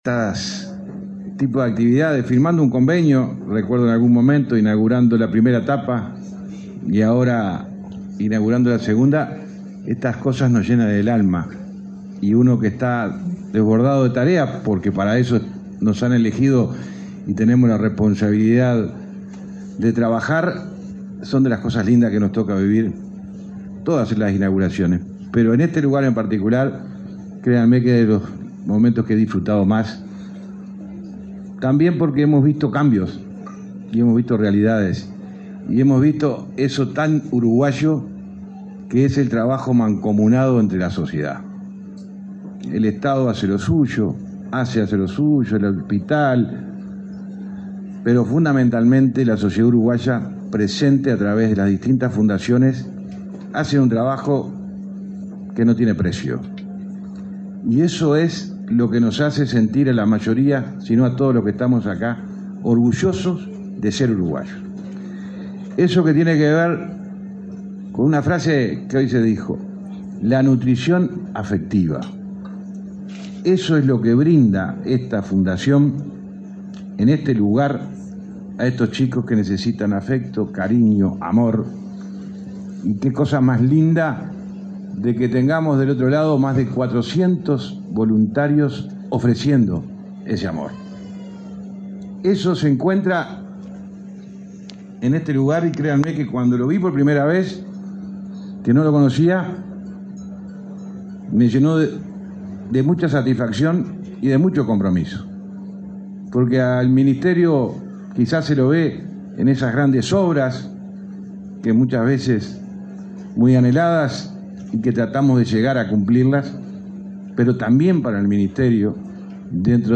Palabfras de autoridades en acto de Fundación Canguro
Este martes 10, el ministro de Transporte, José Luis Falero, y el presidente de ASSE, Marcelo Sosa, participaron en el acto de inauguración de obras